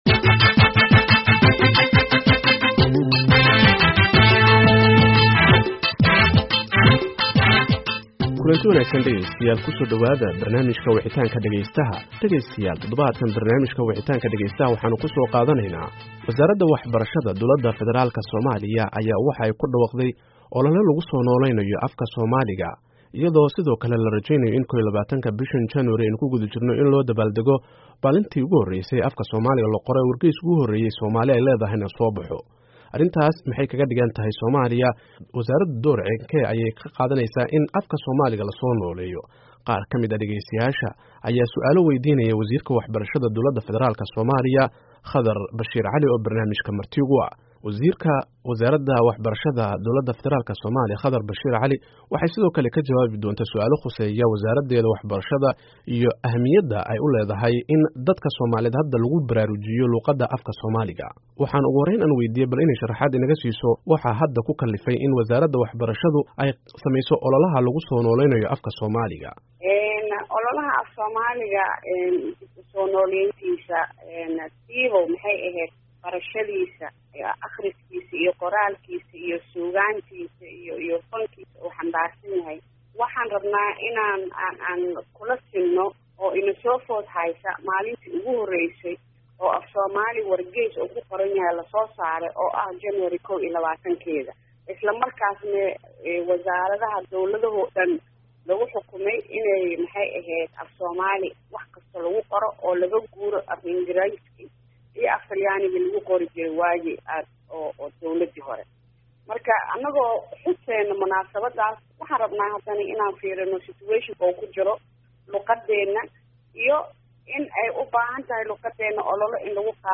Barnaamijka Wicitaanka Dhageystaha ee maanta waxa marti ku ah Wasiirka Waxbarashada iyo Tacliinta Sare ee Somalia Khadar Bashiir Cali, oo ka hadlaysa soo nooleynta Afka Soomaaliga.